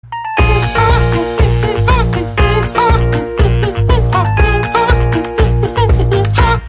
Play, download and share yoda singing original sound button!!!!
yoda-singing.mp3